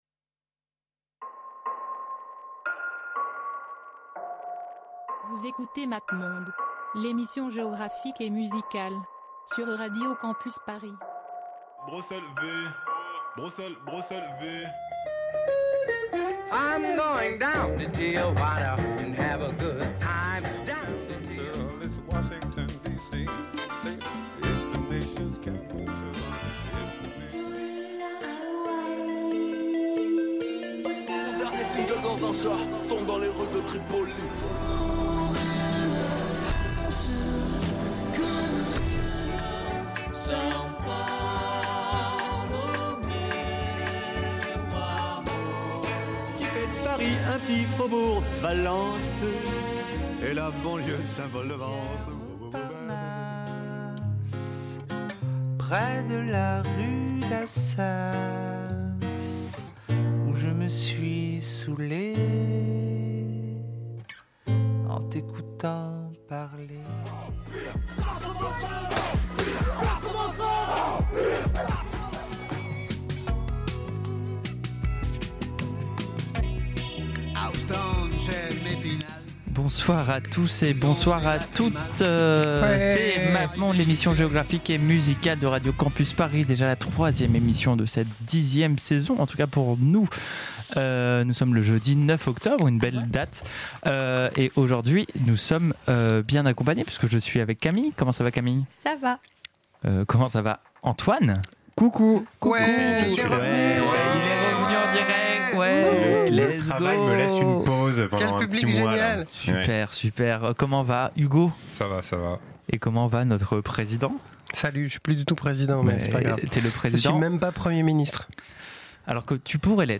Chaque semaine, les globe-trotters musicaux de Mappemonde, l'émission géographique et musicale se proposent de vous faire découvrir la scène musicale d’une ville à travers le monde.
Nos playlists passent par tous les continents et tous les styles de la musique populaire mondialisée.